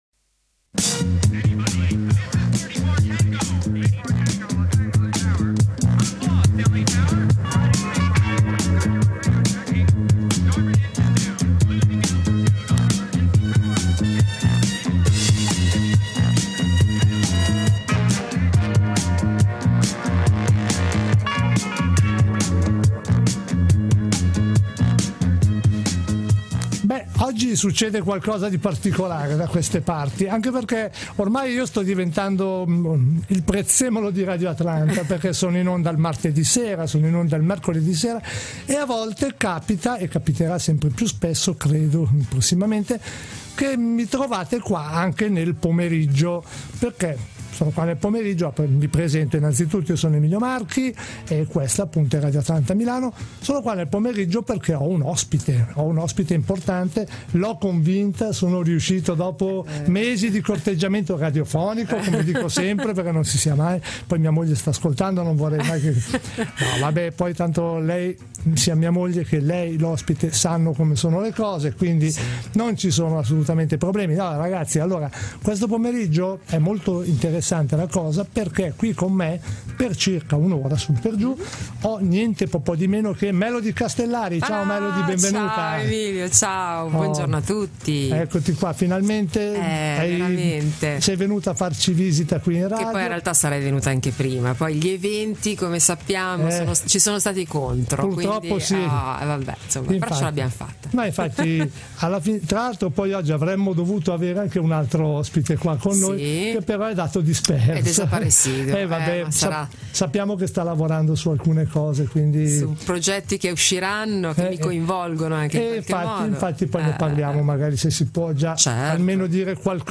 È stata una chiacchierata molto piacevole, in compagnia di una artista estremamente simpatica.